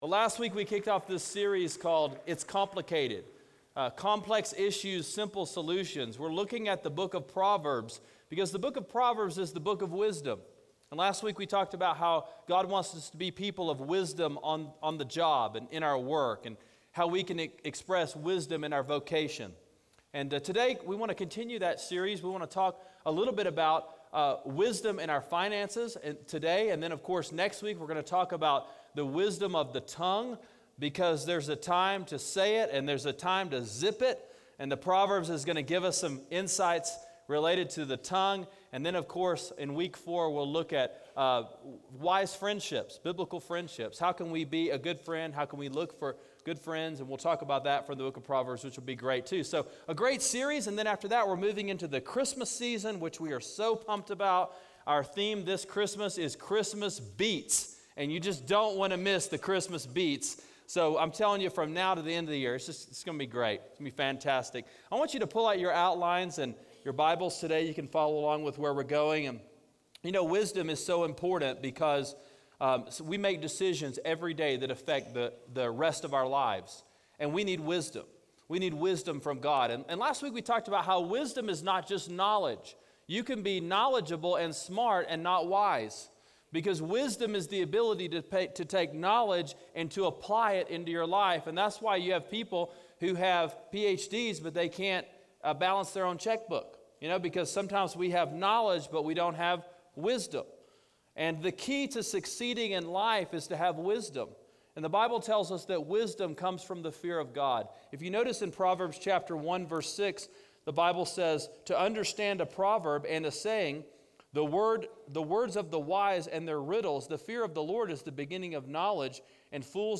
It’s Complicated: Wisdom Of Generosity: Proverbs 1:6-7, 3:5-10, 11:24-25, 16:9, 21:5, 1 Timothy 6:10 – Sermon Sidekick